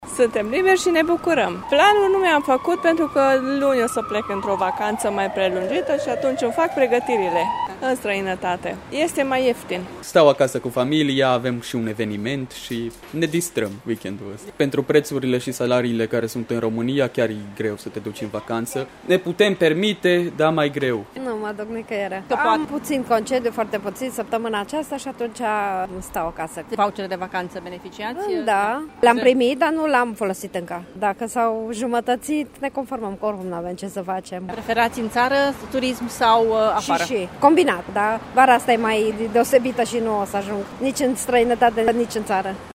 Unii târgumureșeni preferă să stea acasă în minivacanță și chiar au renunțat la concediu anul acesta: